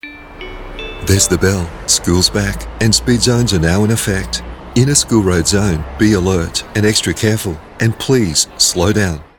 Male
English (Australian), English (Neutral - Mid Trans Atlantic)
Adult (30-50), Older Sound (50+)
Urban